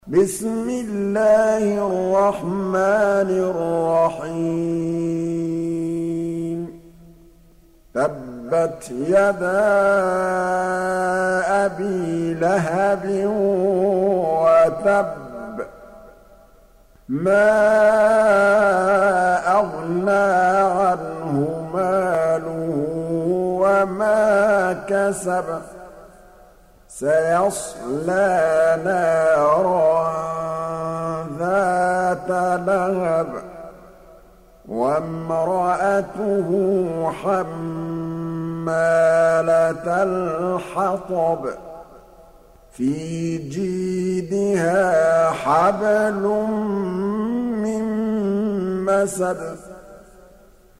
Hafs for Assem حفص عن عاصم
Tarteel المرتّلة